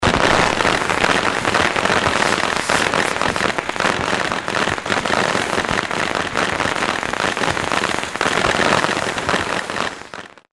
china_firecracker.mp3